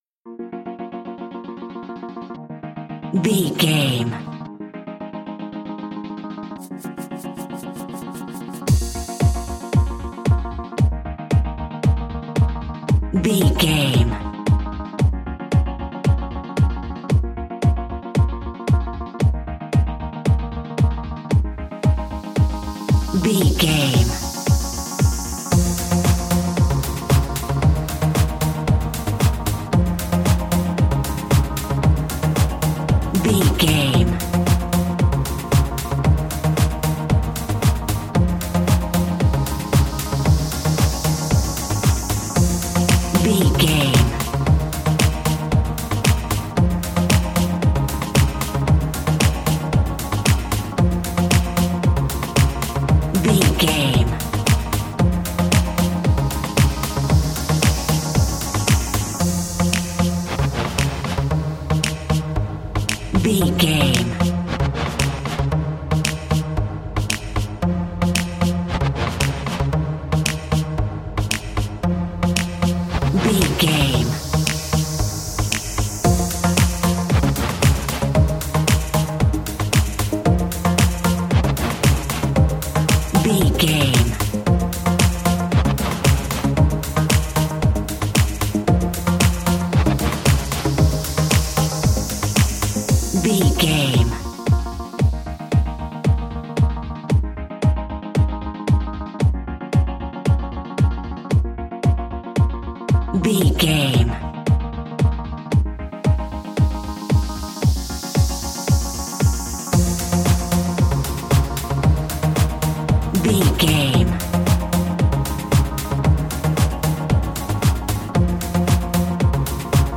Classic reggae music with that skank bounce reggae feeling.
Aeolian/Minor
B♭
instrumentals
laid back
chilled
off beat
drums
skank guitar
hammond organ
percussion
horns